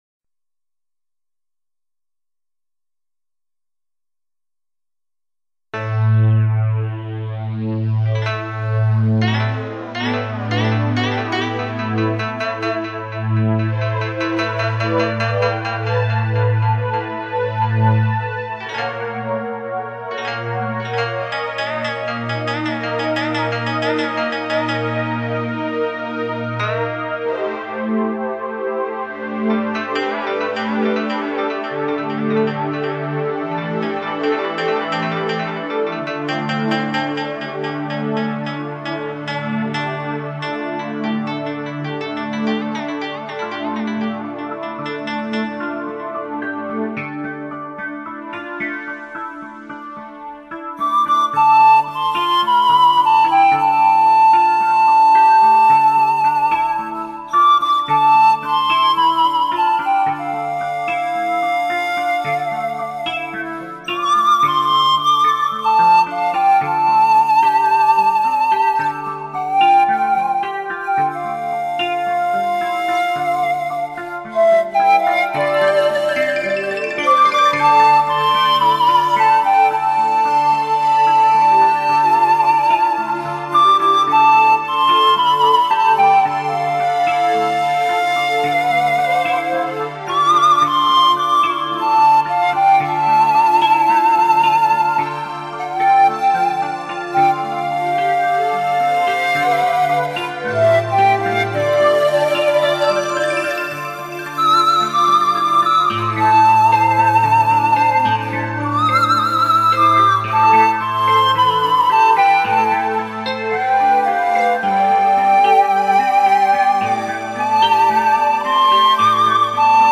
排箫音乐素以柔和著称。
背景音乐由电子乐器承担， 听起来给人以心旷神怡之感。